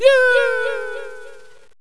voices / death / happy